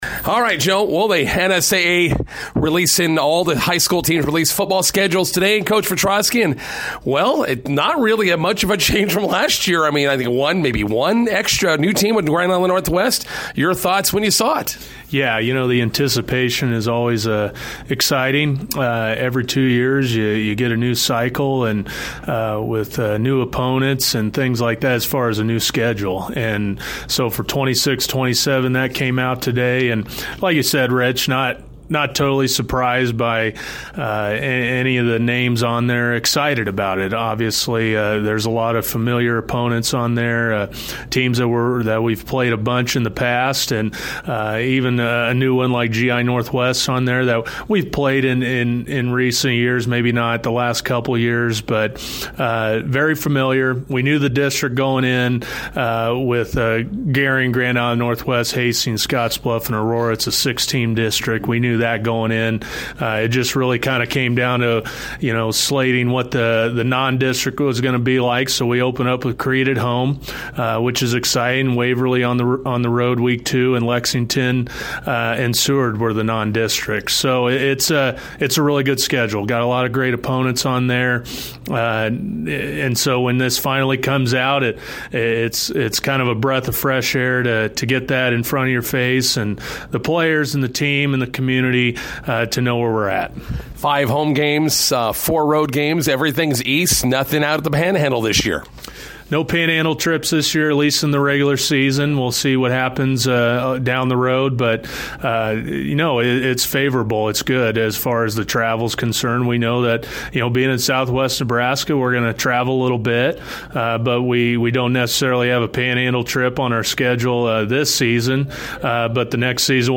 INTERVIEW: McCook Bison 2026 fall football schedule released today.